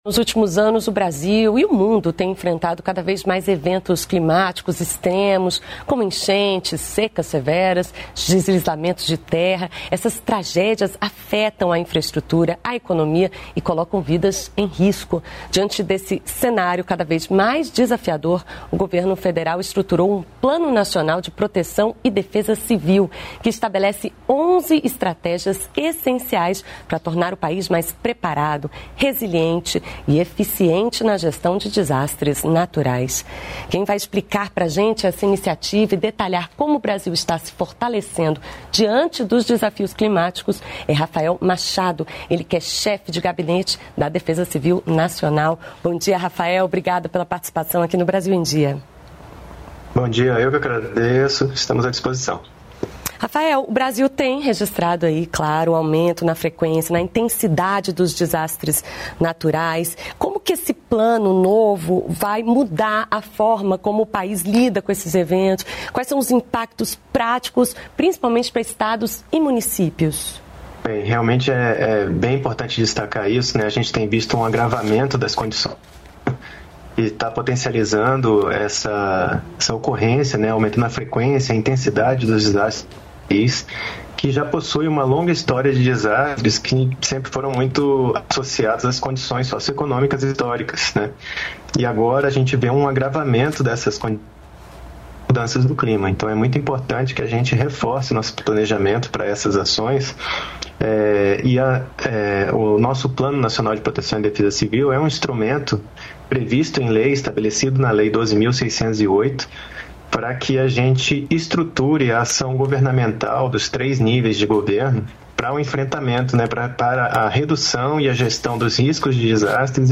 Brasil em Dia - Entrevista